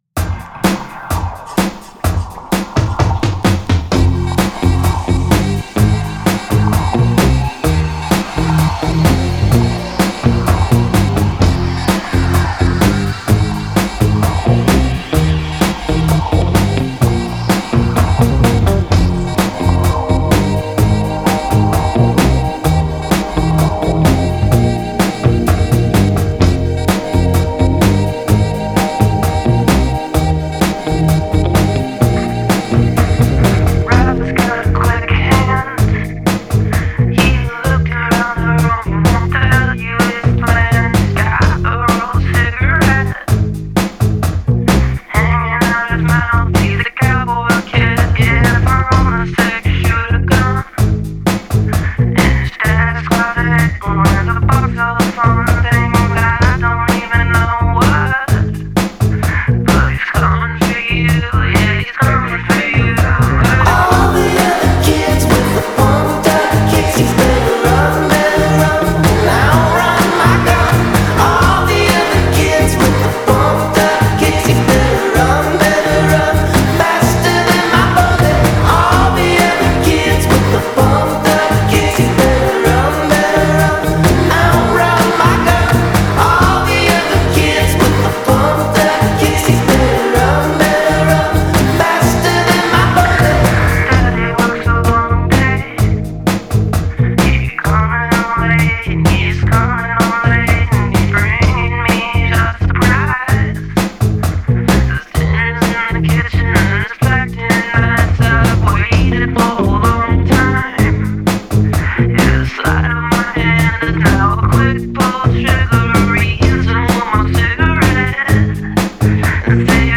Genre................: Pop